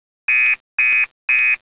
warning.wav